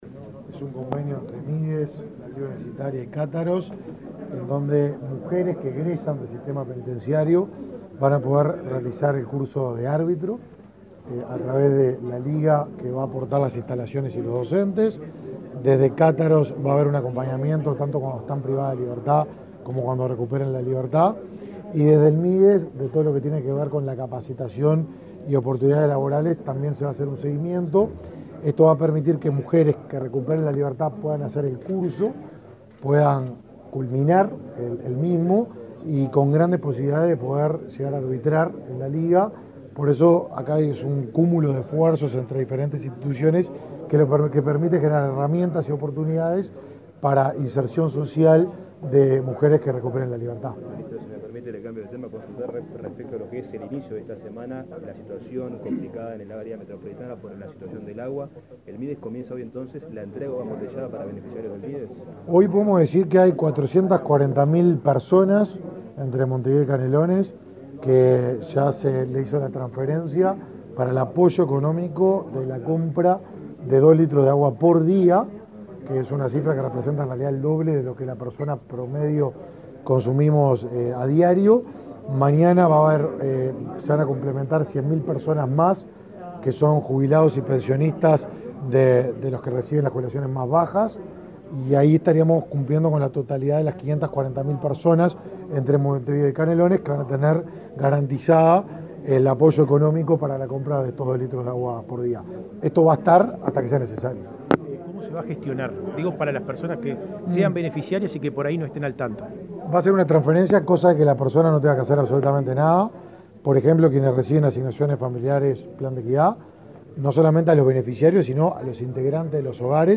Declaraciones del ministro de Desarrollo Social, Martín Lema
El titular de la citada cartera, Martín Lema, participó en la firma y luego dialogó con la prensa.